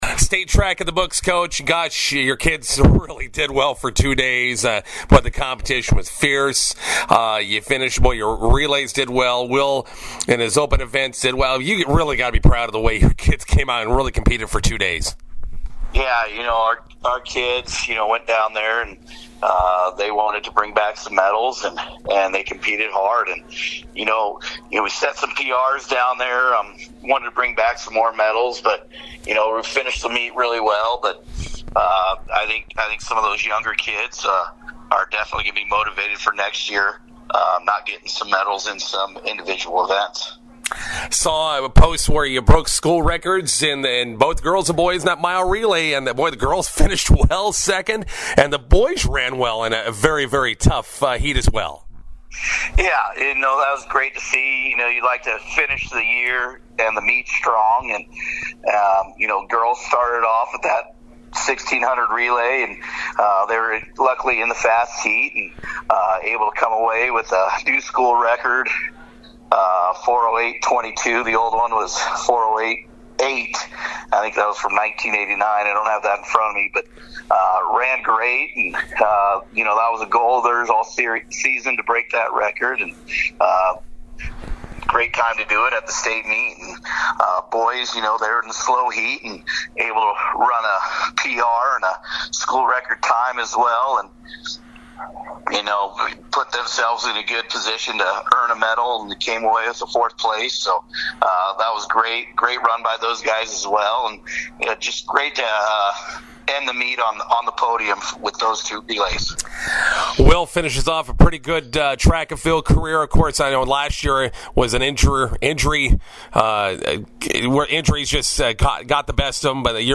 INTERVIEW: Arapahoe Track and Field ends their spring season with state medals in Omaha.